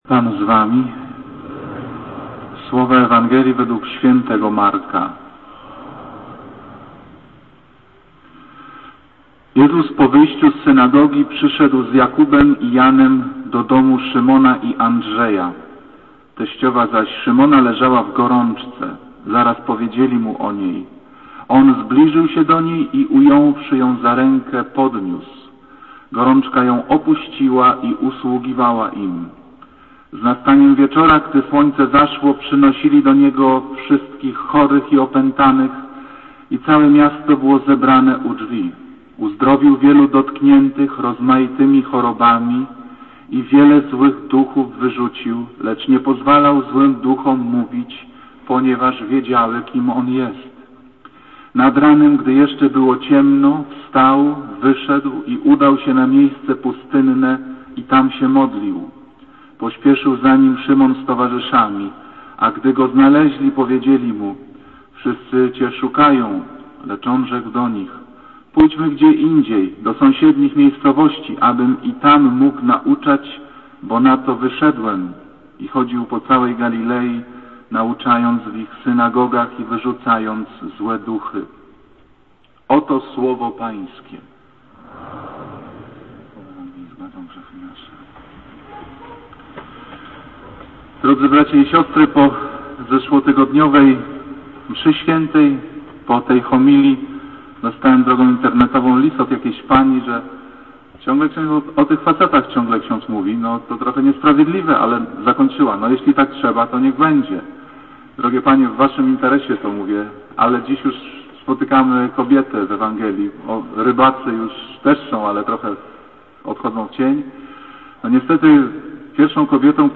Homilie akademickie